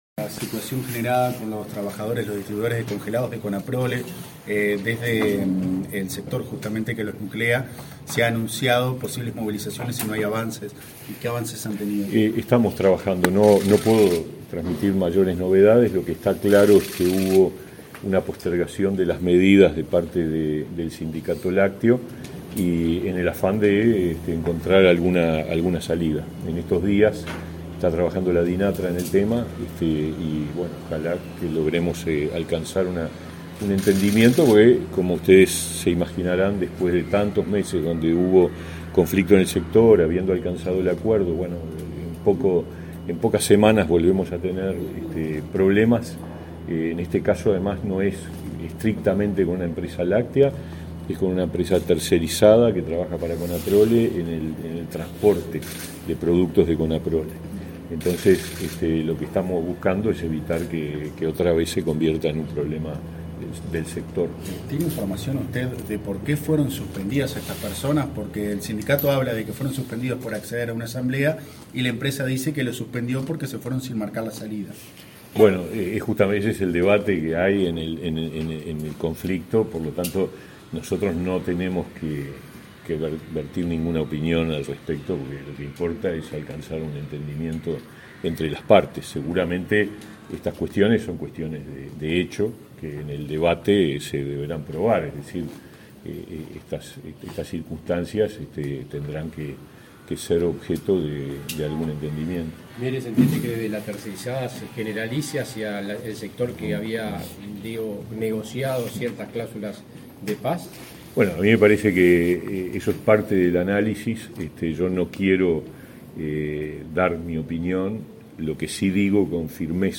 Declaraciones del ministro de Trabajo, Pablo Mieres
Declaraciones del ministro de Trabajo, Pablo Mieres 29/09/2022 Compartir Facebook X Copiar enlace WhatsApp LinkedIn El ministro de Trabajo y Seguridad Social, Pablo Mieres, participó de la presentación del programa Colectivos Vulnerables, enmarcado en la Ley de Promoción del Empleo, por el que las empresas podrán acceder a subsidios mayores si contratan a personas con más dificultades de acceso a oportunidades. Luego, dialogó con la prensa.